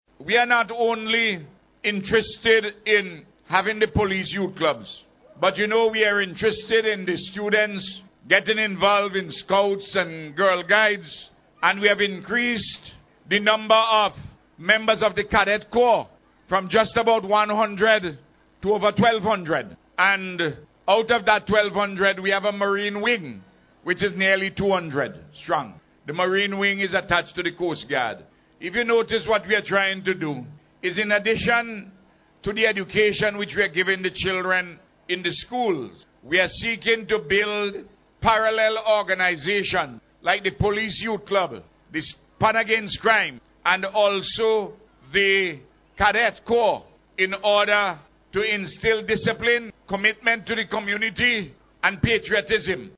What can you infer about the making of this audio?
The launch was held during a concert at the Georgetown Police Station on Saturday.